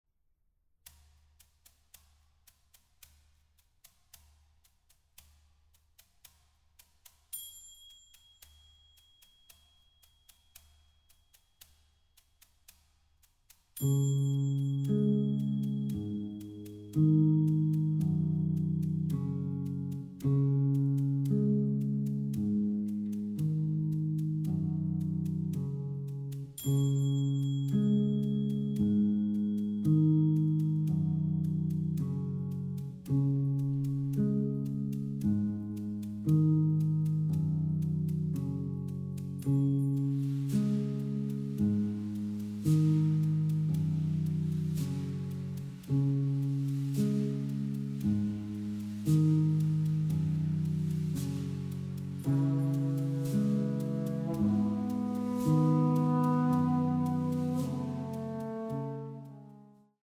Historical Drums
Recording: Gut Hohen Luckow, 2024